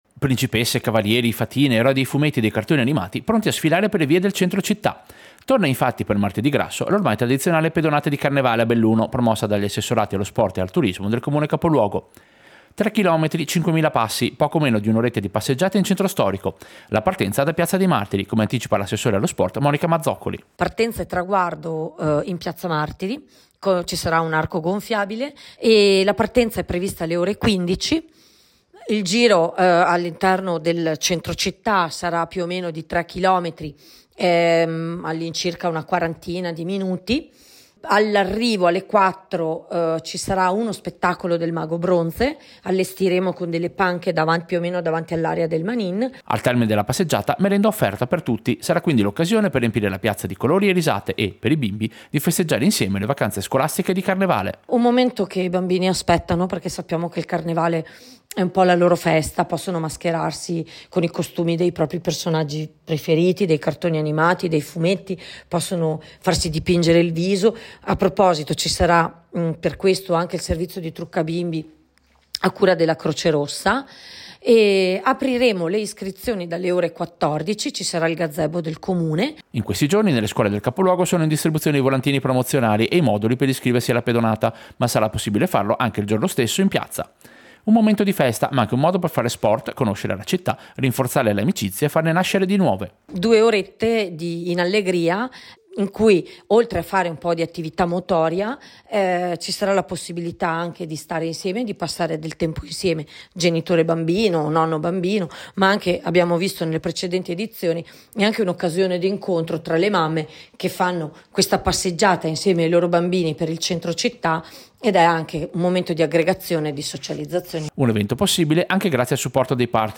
Servizio-Pedonata-Carnevale-Belluno.mp3